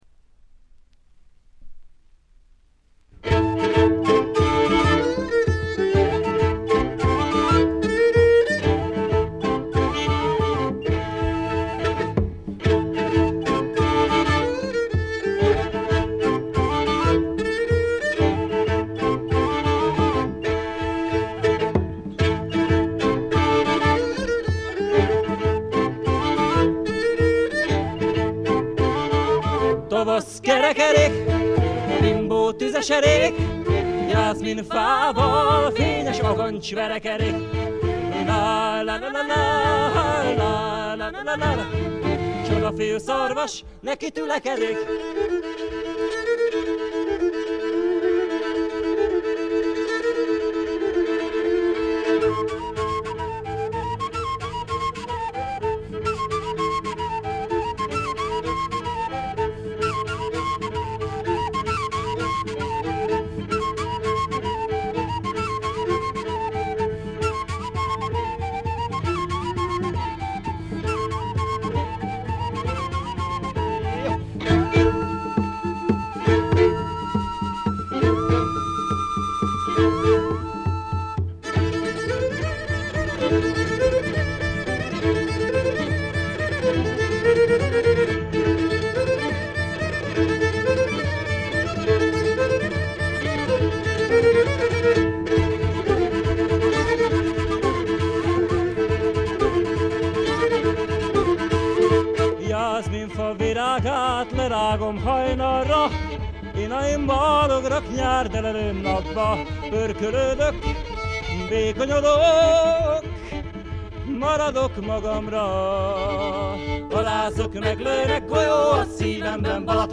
部分試聴ですが、軽微なチリプチが少し出る程度。
スイスのフォーク・フェスティヴァルの2枚組ライヴ盤。
試聴曲は現品からの取り込み音源です。